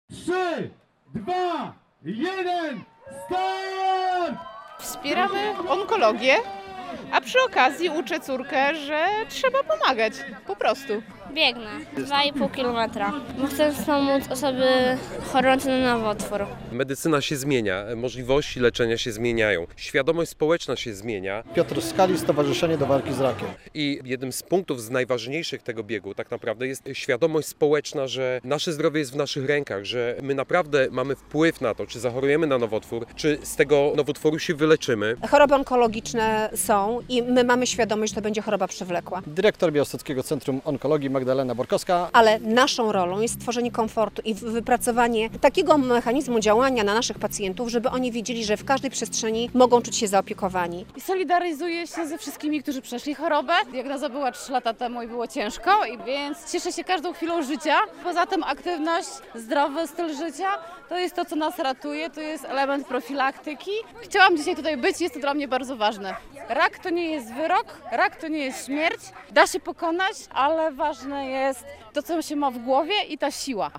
Onko Run w Supraślu - relacja